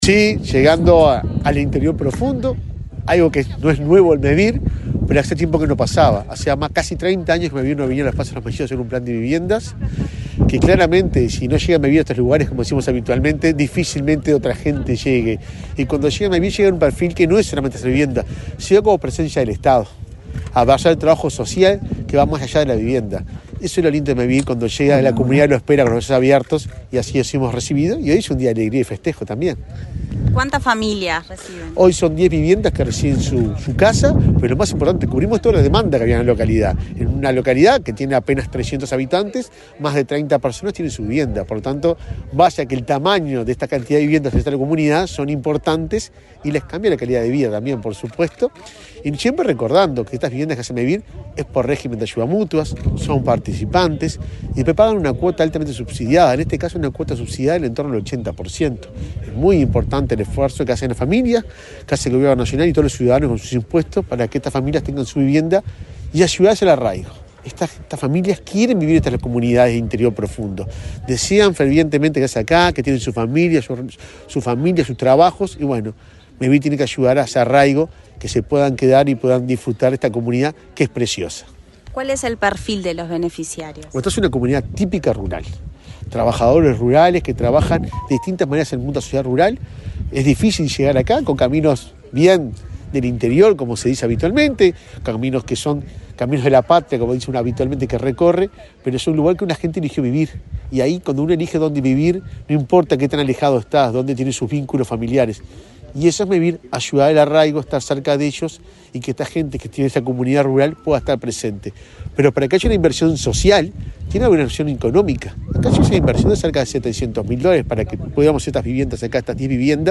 Entrevista al presidente de Mevir, Juan Pablo Delgado
El presidente de Mevir, Juan Pablo Delgado, dialogó con Comunicación Presidencial antes de participar en el acto de inauguración de 10 viviendas